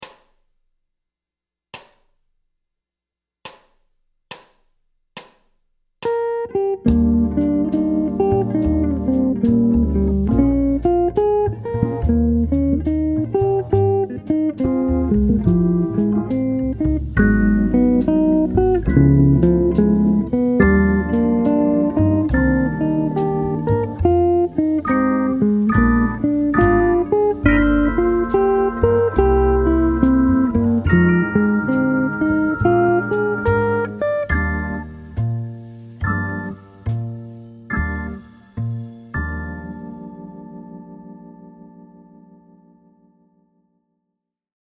En jeu libre
Arrivé à la fin du 4ème exercice, vous devez être en capacité de jouer le morceau en utilisant pour chaque mesure, un motif d’exercice différent.